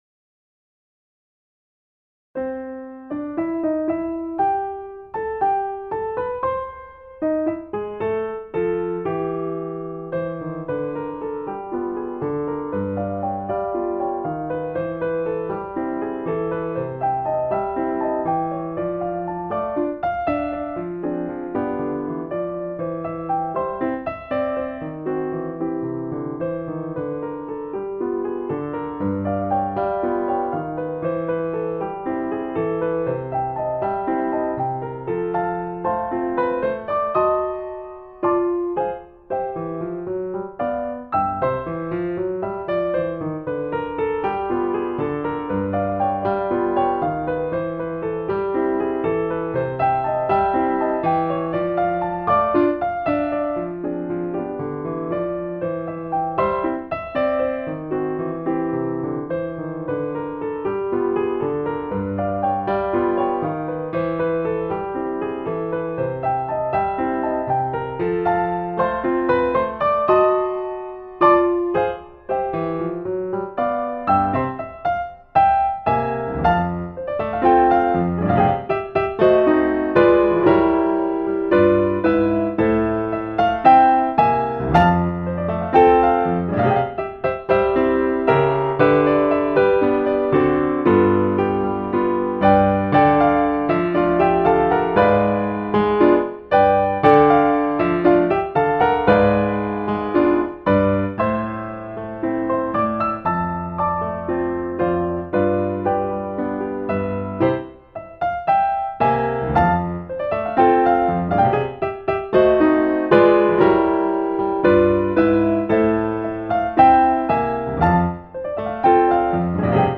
piano and keyboards